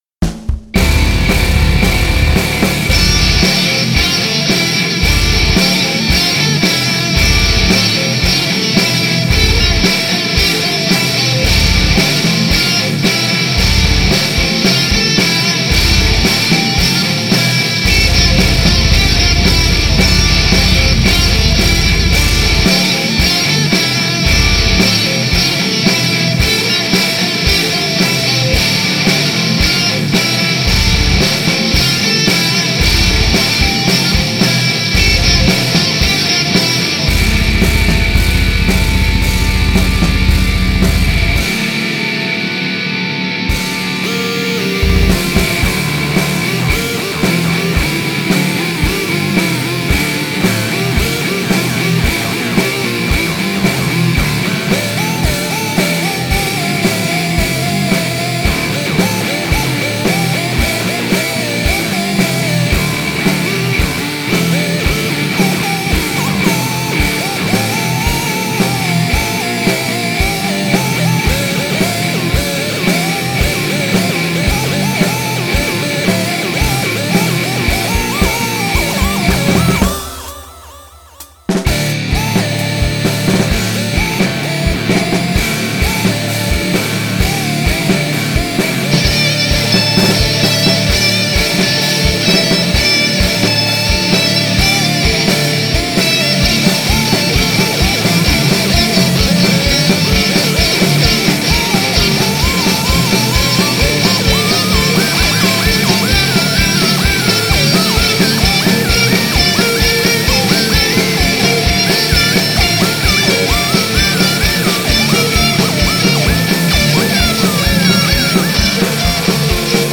Avant-guarde tooth-crushing toothpaste